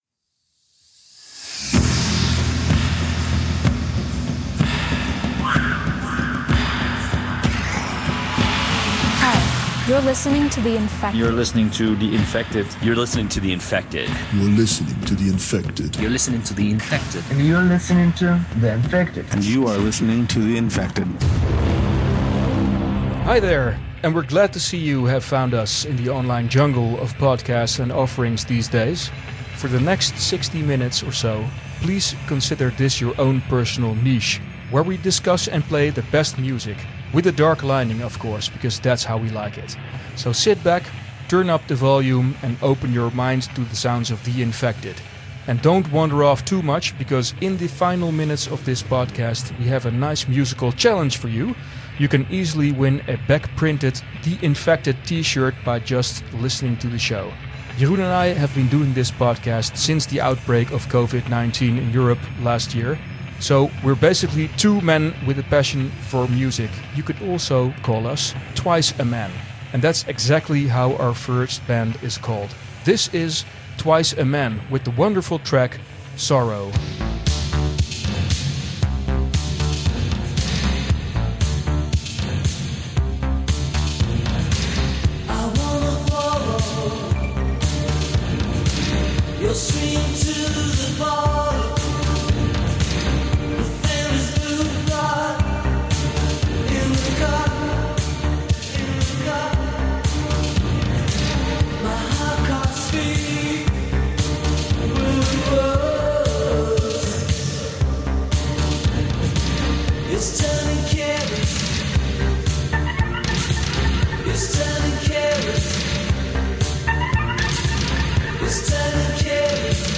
We Don't Want To Live Forever The Infected: Post-Punk, Alternative, New Wave, Goth Music Podcast With Background Stories And Tips podcast